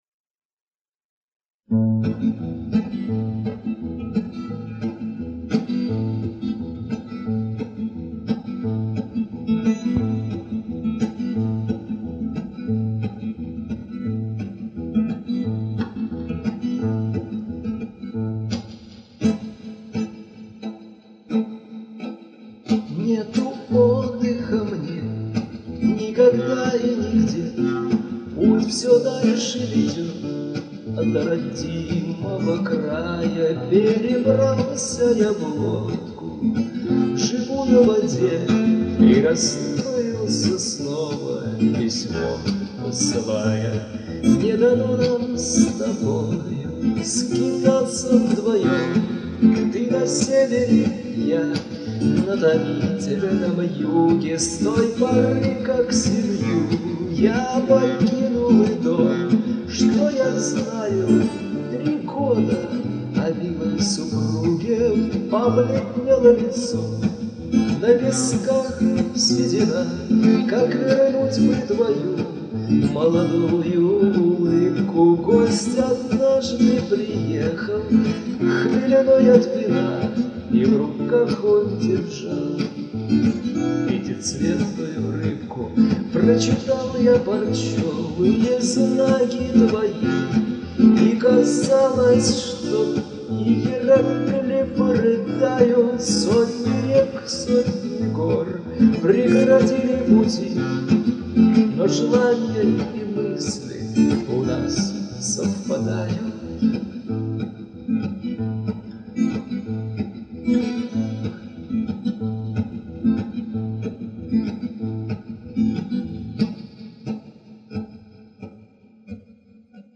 2001 г. (весна), Гомель, соло
Домашняя акустика
Качество записи - нормально